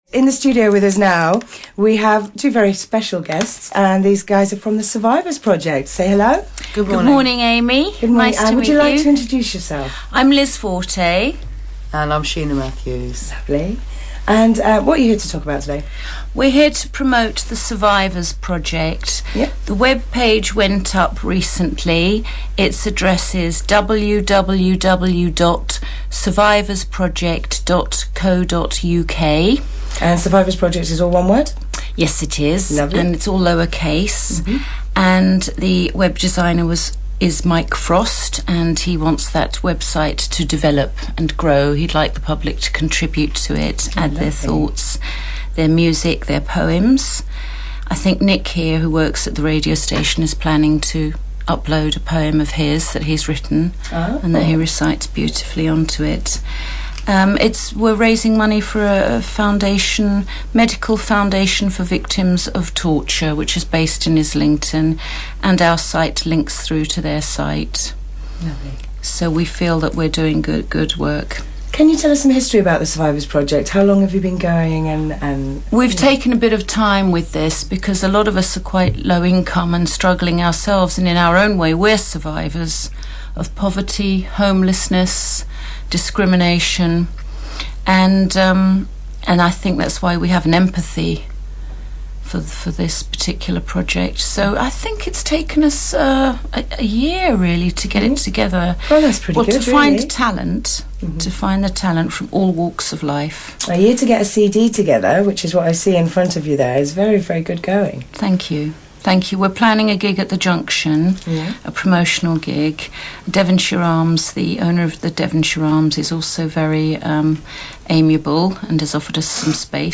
209Radio Interview
Checkout the 209 Radio Interview now.